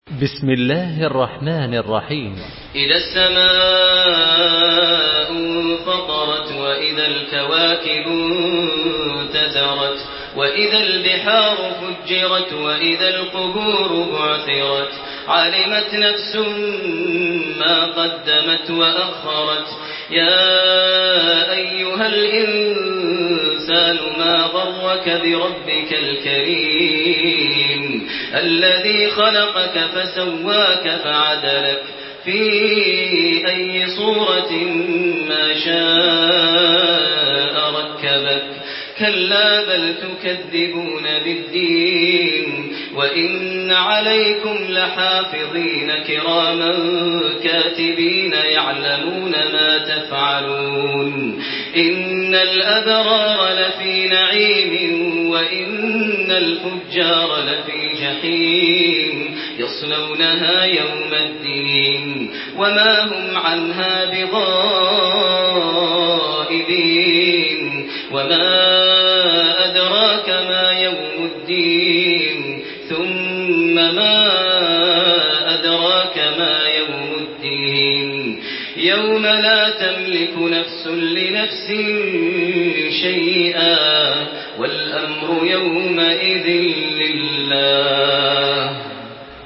تحميل سورة الانفطار بصوت تراويح الحرم المكي 1428
مرتل